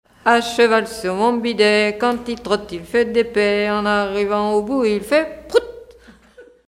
formulette enfantine : sauteuse
Genre brève
Pièce musicale éditée